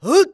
xys蓄力1.wav
xys蓄力1.wav 0:00.00 0:00.35 xys蓄力1.wav WAV · 30 KB · 單聲道 (1ch) 下载文件 本站所有音效均采用 CC0 授权 ，可免费用于商业与个人项目，无需署名。
人声采集素材